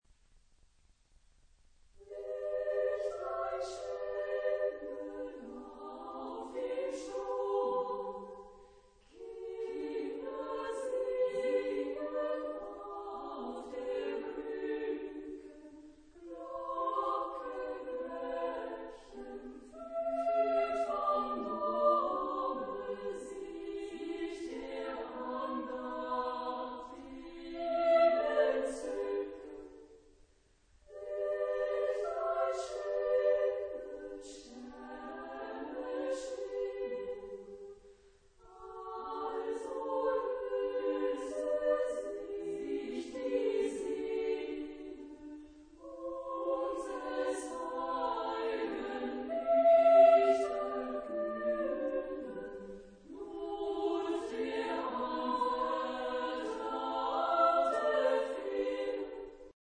Genre-Stil-Form: Liedsatz ; Kinderlied
Charakter des Stückes: adagio ; ausdrucksvoll ; langsam
Chorgattung: SSA  (3 Frauenchor Stimmen )
Tonart(en): G-Dur